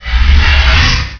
sound / wraith / attack1.wav
attack1.wav